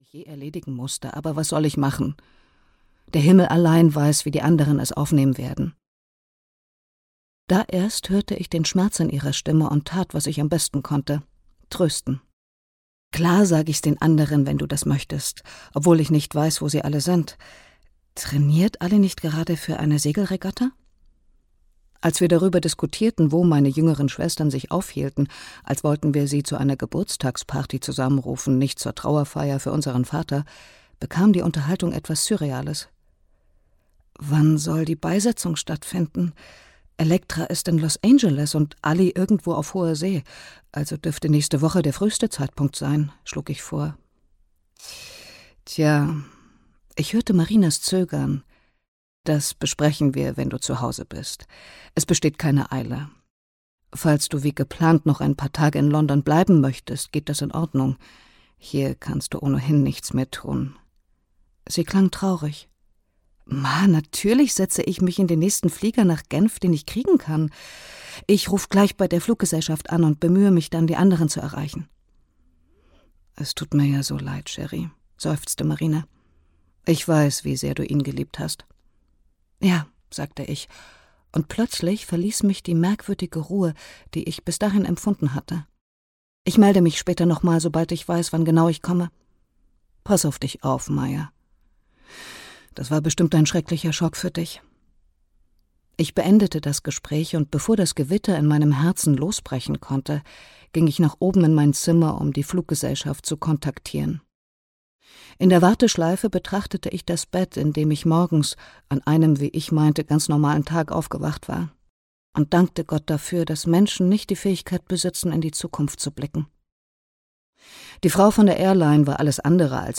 Die sieben Schwestern (DE) audiokniha
Ukázka z knihy